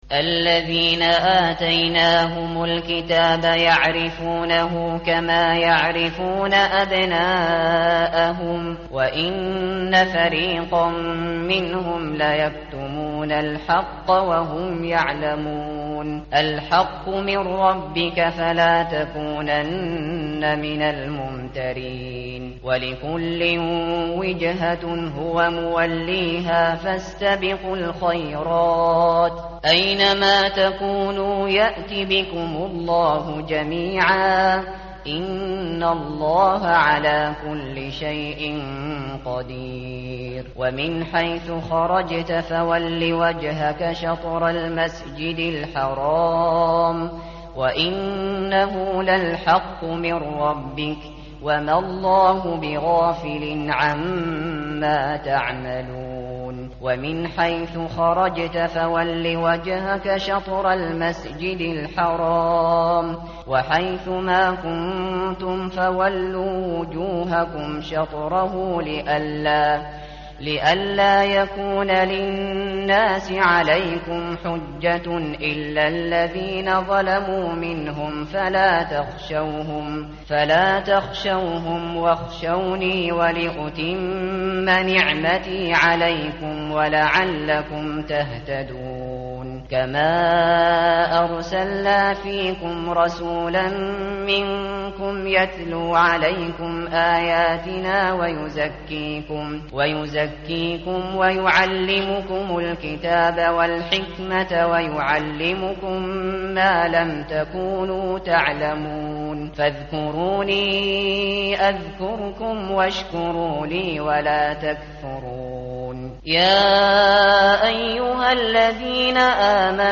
متن قرآن همراه باتلاوت قرآن و ترجمه
tartil_shateri_page_023.mp3